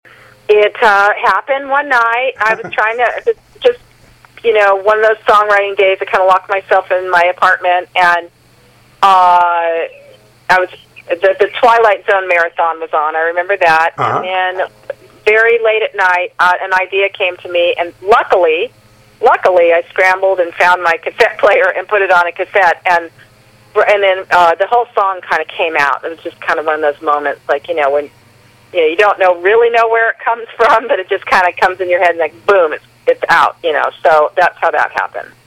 Interview with Charlotte Caffey of The Go-Go's, about how she wrote such hit songs as "We Got The Beat," "Vacation" and "Head Over Heels."
Charlotte_Caffey_interview_excerpt.mp3